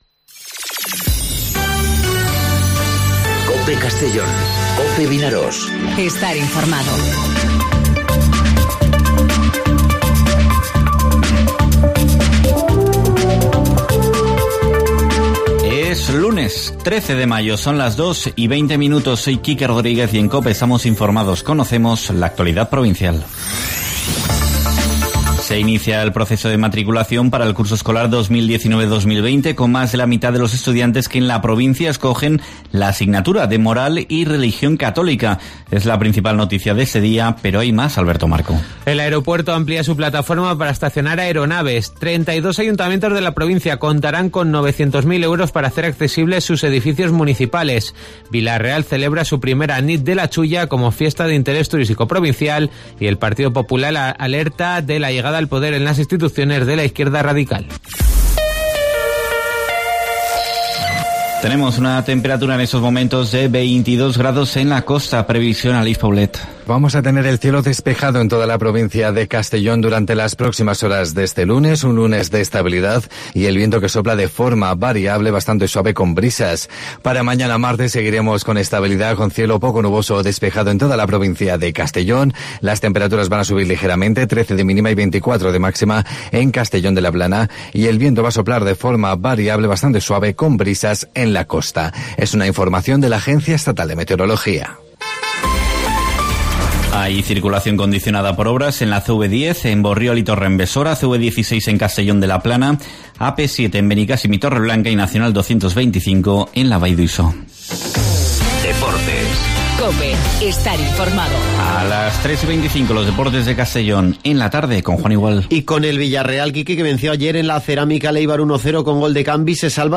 Informativo 'Mediodía COPE' en Castellón (13/05/2019)